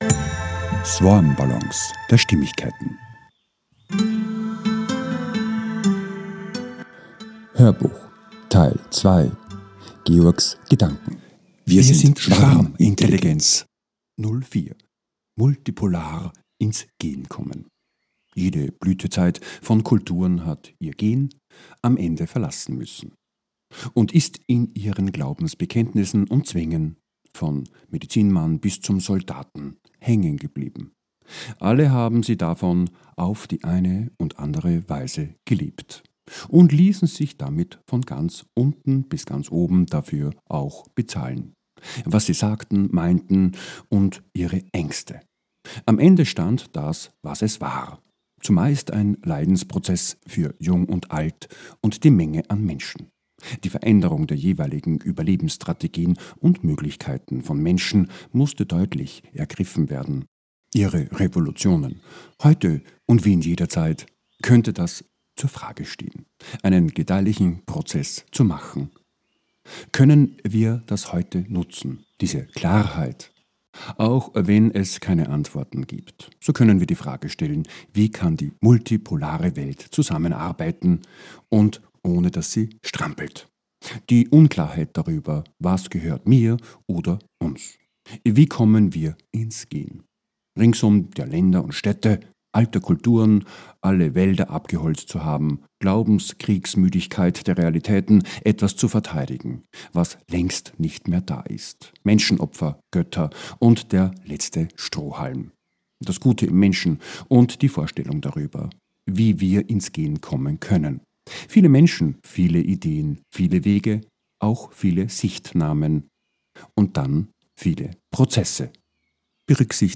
HÖRBUCH TEIL 2 - 04 - WIR SIND SCHWARMINTELLIGENZ 2 - MULTIPOLAR INS GEHEN - 11.01.26, 14.00 ~ SwarmBallons A-Z der Stimmigkeit Podcast